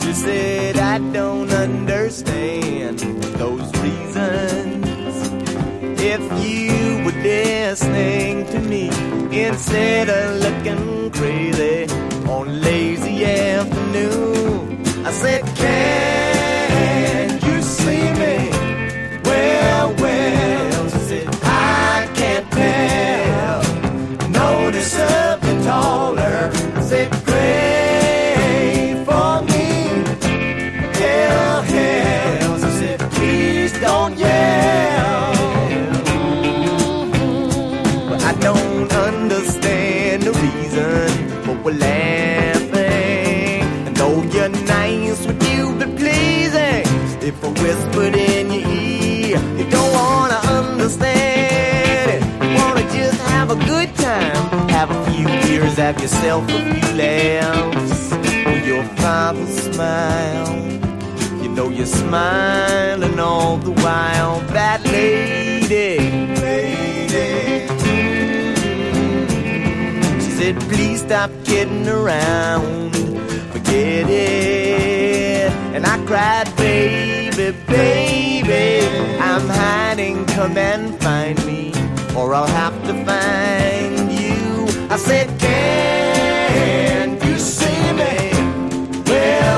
陽だまりメロウ・フォークS.S.W.必携盤！